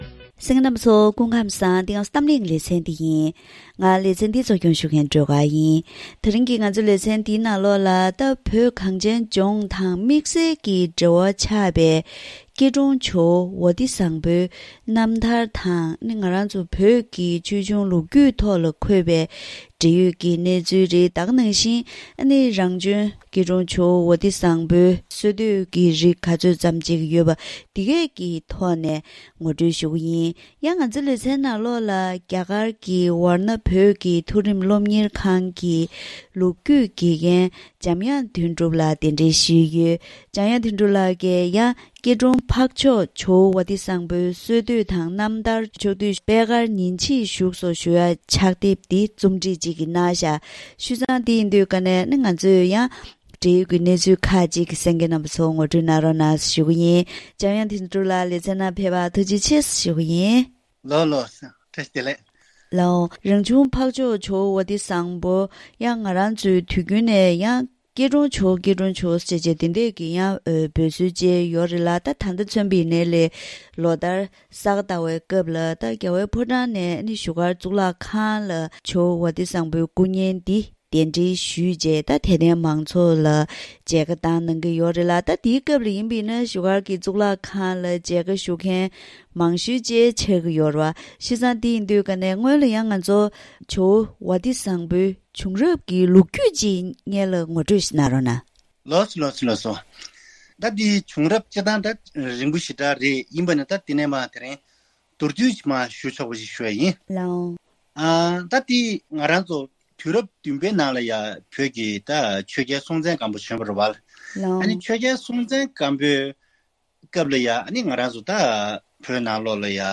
ད་རིང་གི་གཏམ་གླེང་ལེ་ཚན་ནང་བོད་གངས་ཅན་ལྗོངས་དང་དམིགས་བསལ་གྱི་འབྲེལ་བ་ཆགས་པའི་སྐྱིད་གྲོང་ཇོ་བོ་ཝ་ཏི་བཟང་པོའི་རྣམ་ཐར་དང་བོད་ཀྱི་ཆོས་བྱུང་ལོ་རྒྱུས་ཐོག་འཁོད་པའི་འབྲེལ་ཡོད་གནས་ཚུལ། དེ་བཞིན་རང་བྱོན་ཇོ་བོ་ཝ་ཏི་བཟང་པོའི་གསོལ་བསྟོད་ཇི་ཙམ་བྱུང་ཡོད་སྐོར་ལ་ངོ་སྤྲོད་ཞུས་པ་ཞིག་གསན་རོགས་གནང་།